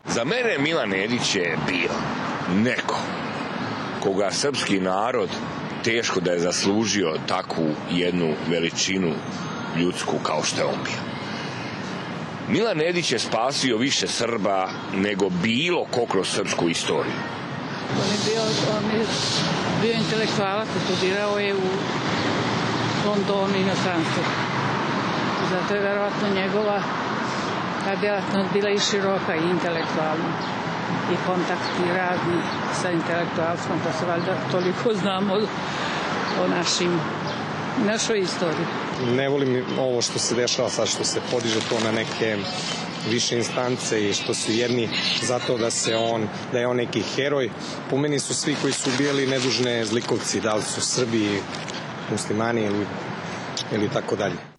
Gledišta o ulozi Milana Nedića su različita, potvrdila je anketa sa građanima Beograda.